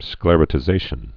(sklĕrə-tĭ-zāshən)